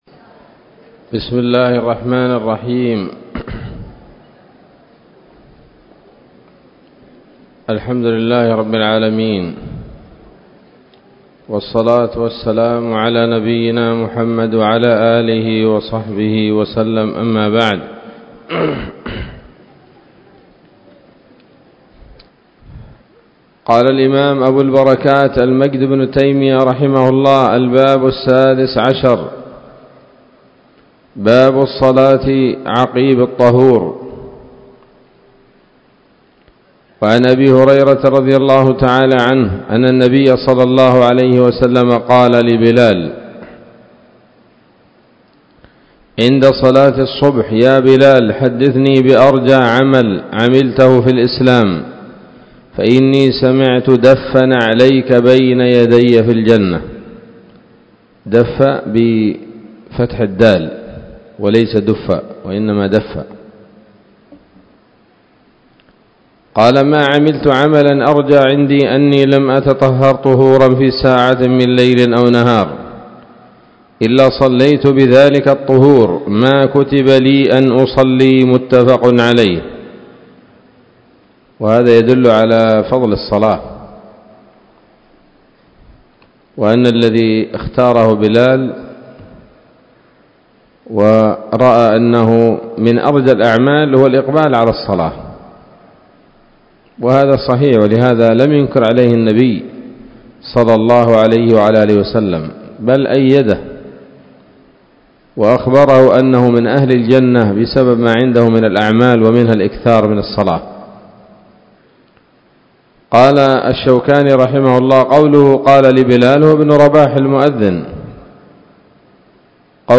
الدرس السادس والثلاثون من ‌‌‌‌أَبْوَابُ صَلَاةِ التَّطَوُّعِ من نيل الأوطار